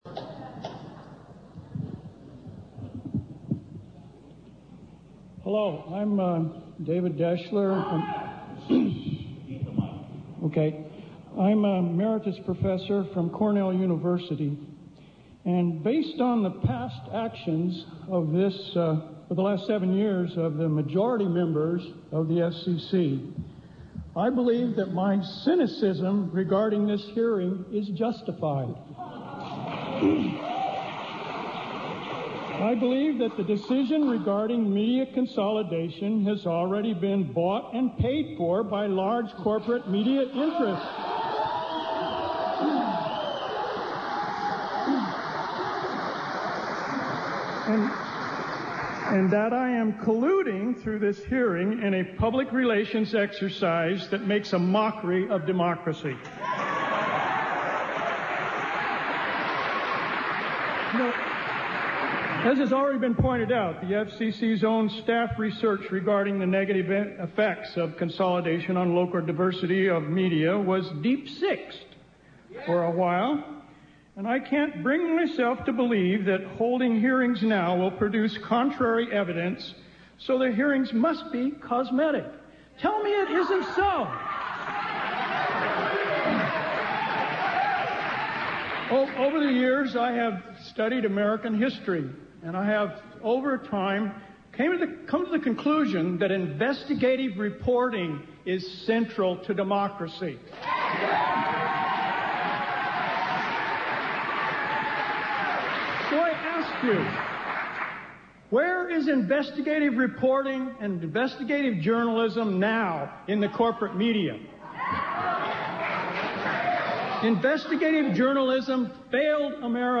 §FCC holds Seattle public hearings on deregulation
Three more speakers make their case against further media consoldiation during the public comment period (7 min)